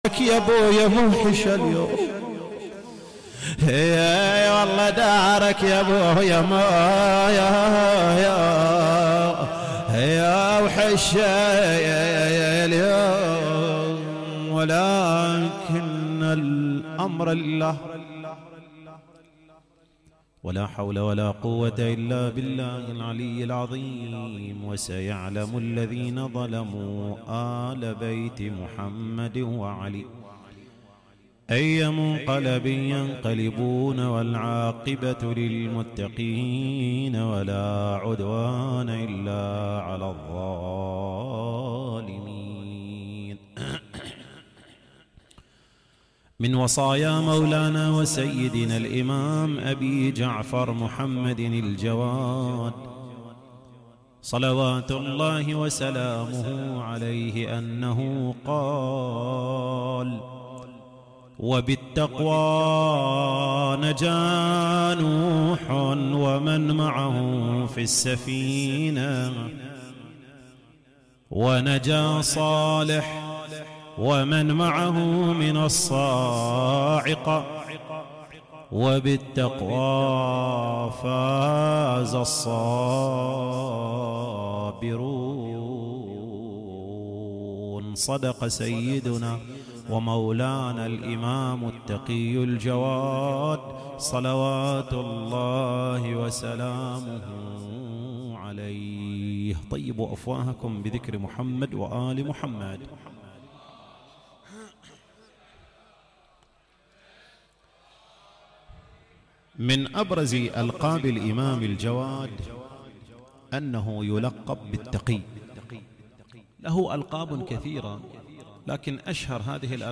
تغطية شاملة: مجلس العزاء يوم وفاة الإمام محمد الجواد ع 1440هـ